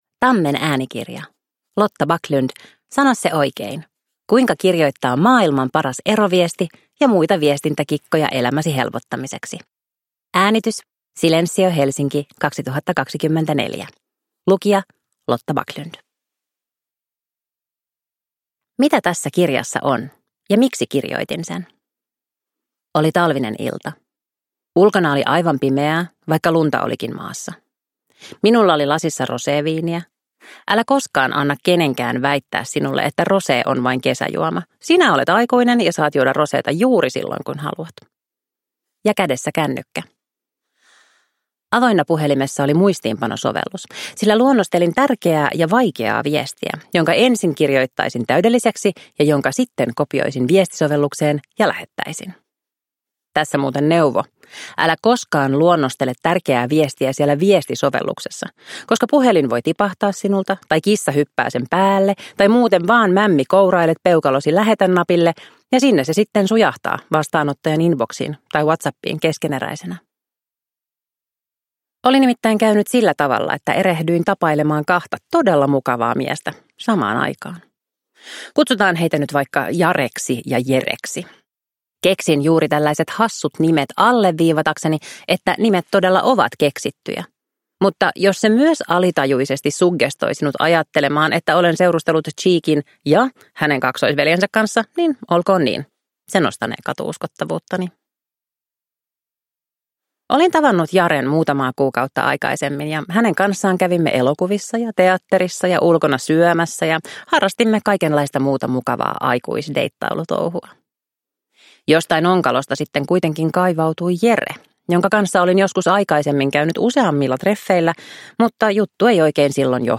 Sano se oikein – Ljudbok